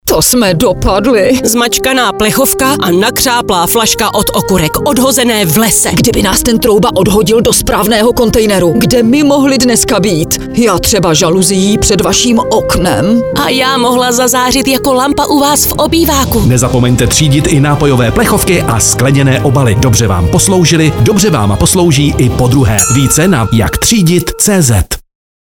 Rádio spot Plechovka a lahev